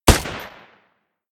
ar_firing.ogg